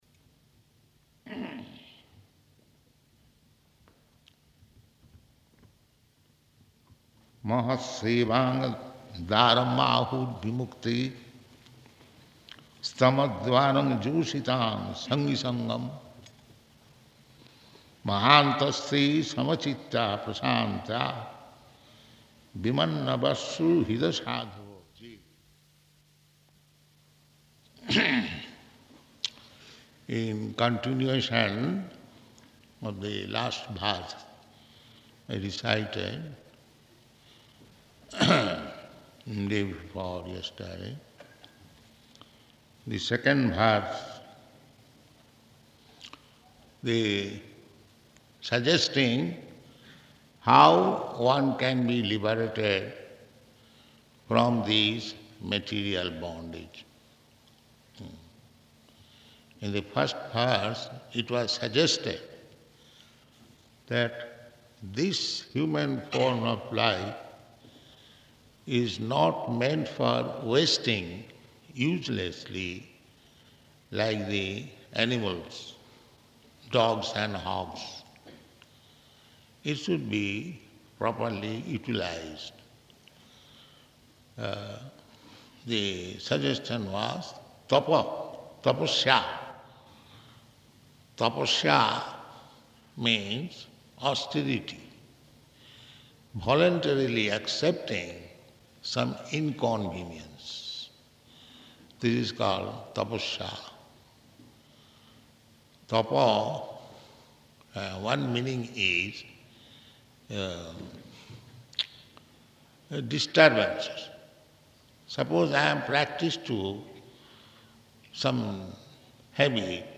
Location: Johannesburg